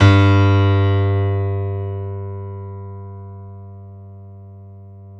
SG1 PNO  G 1.wav